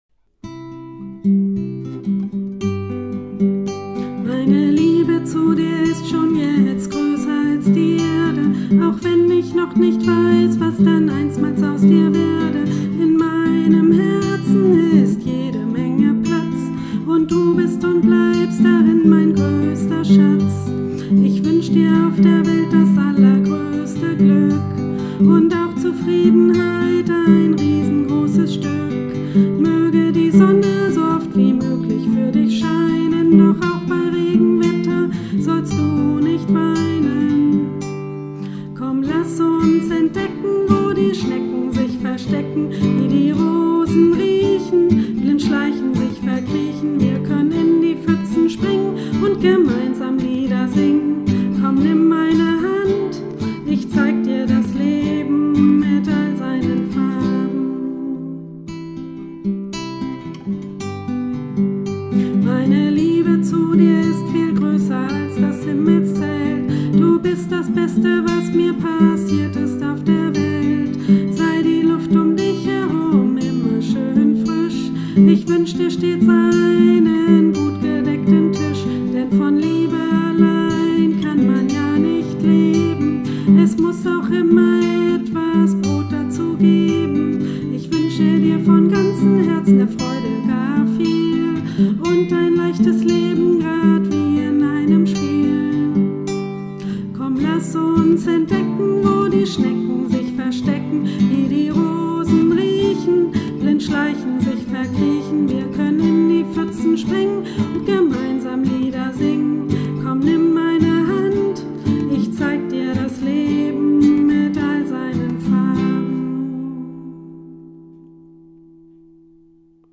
Liebeslied